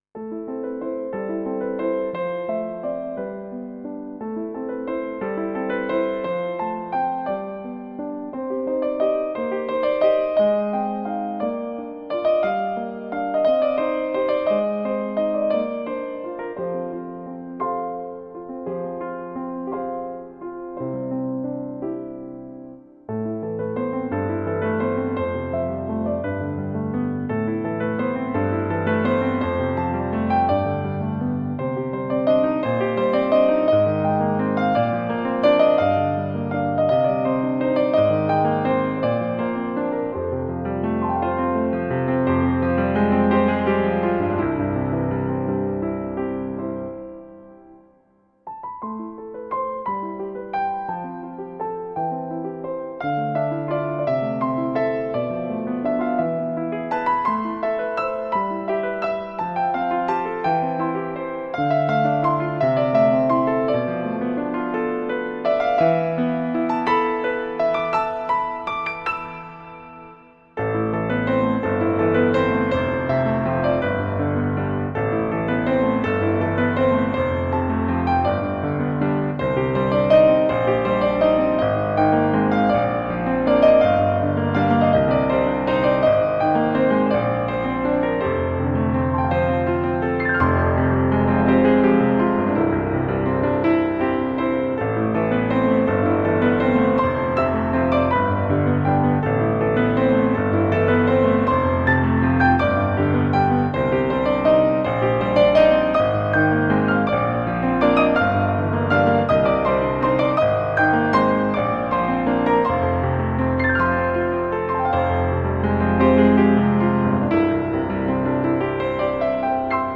New age romantic piano music.